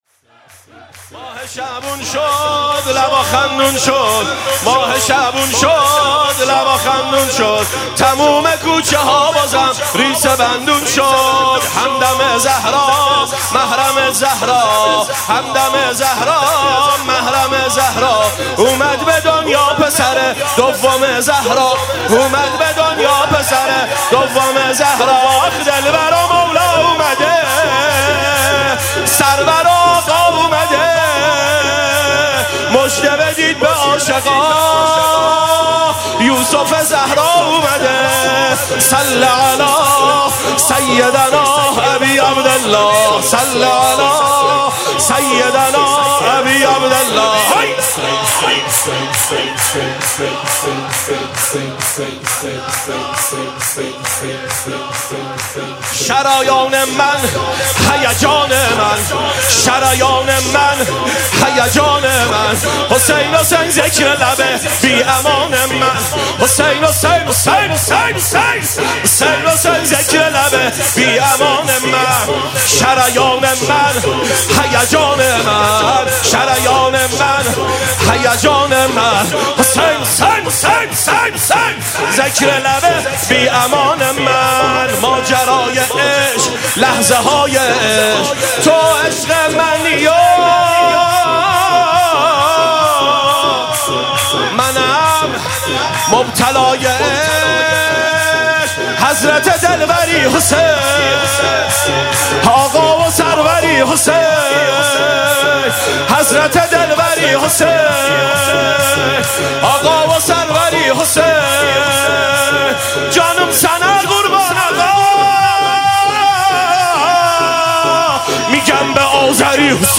سرود
جشن میلاد حضرت عباس علیه السلام